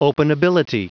Prononciation du mot openability en anglais (fichier audio)
Prononciation du mot : openability